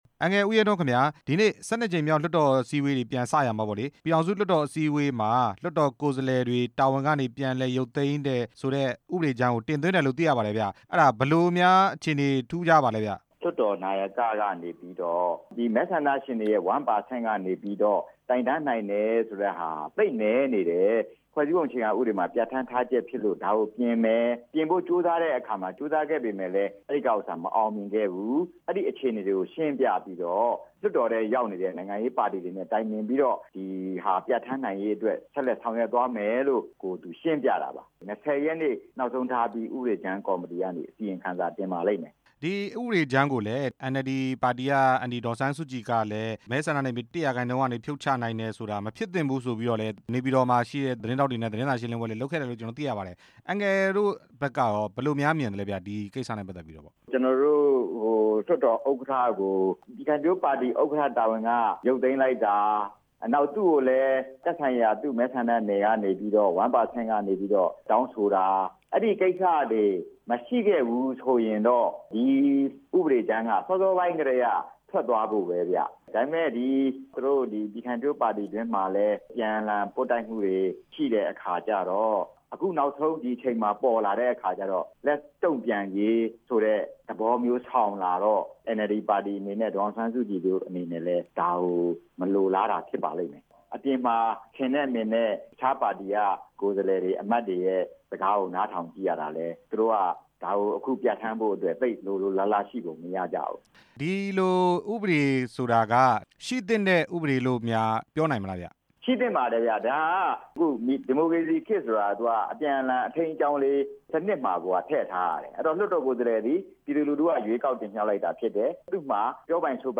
ကိုယ်စားလှယ် ရုပ်သိမ်းဥပဒေ အကြောင်းမေးမြန်းချက်